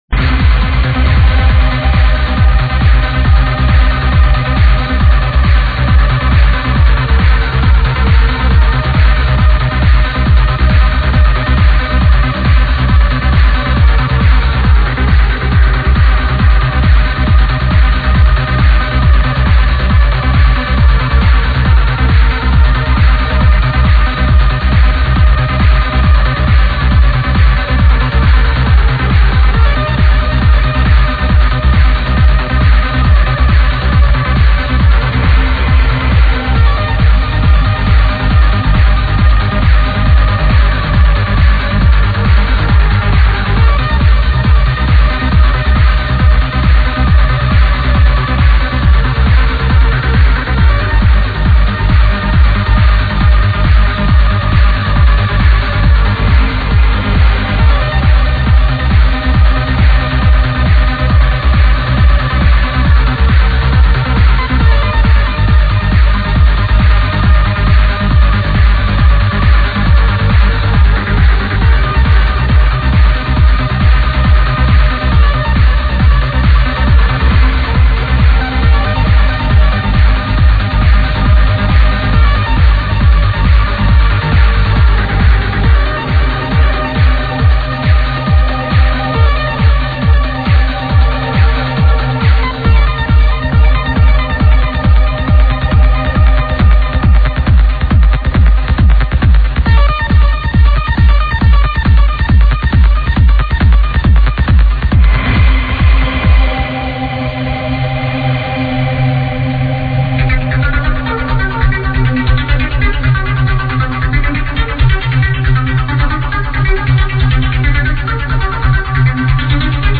in a live set